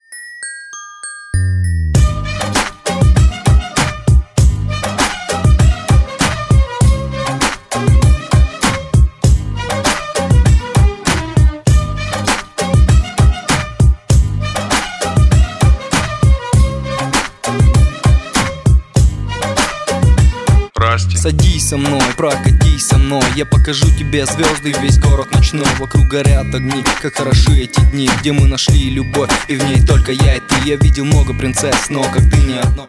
• Качество: 192, Stereo
восточные мотивы
русский рэп